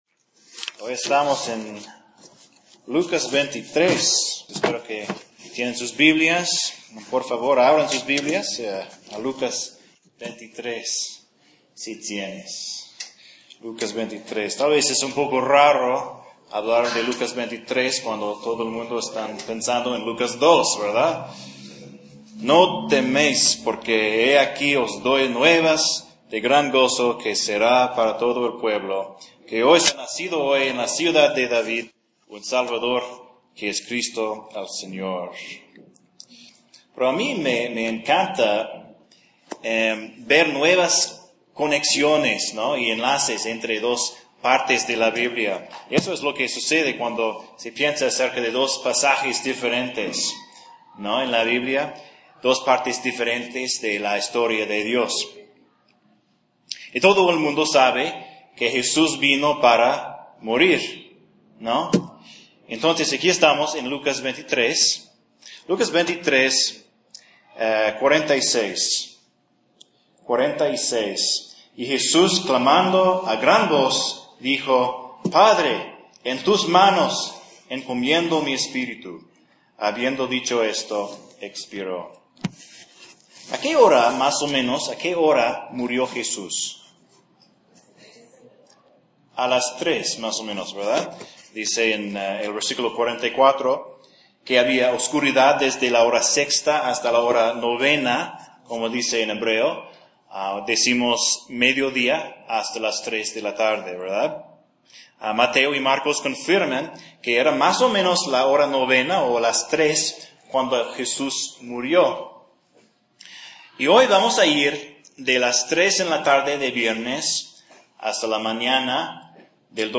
La Tumba (sermón) - En la Biblia